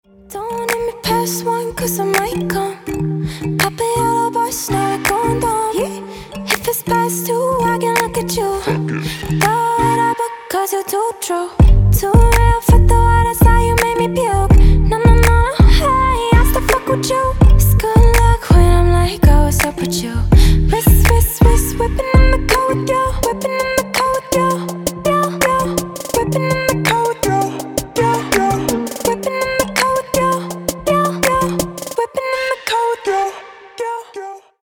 • Качество: 224, Stereo
женский вокал
dance
EDM
club
vocal